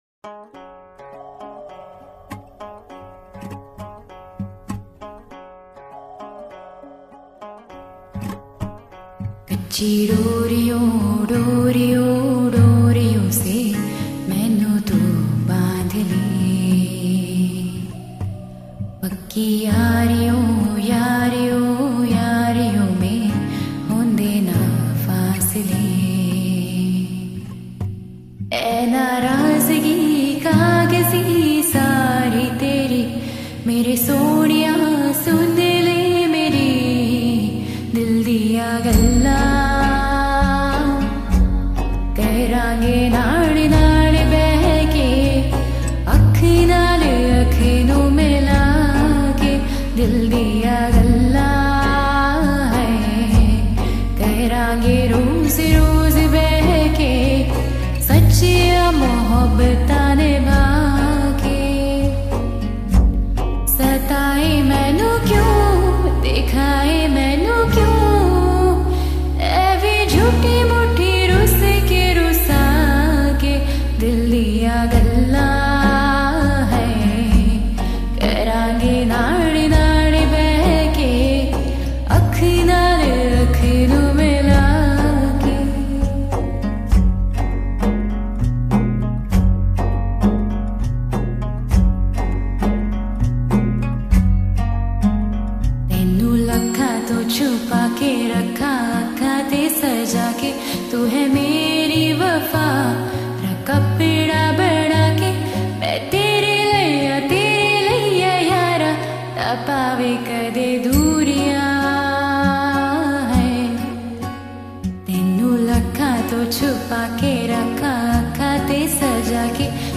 Simply superb,Nice voice !!